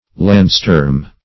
Landsturm \Land"sturm`\, n. [G. See Land; Storm.]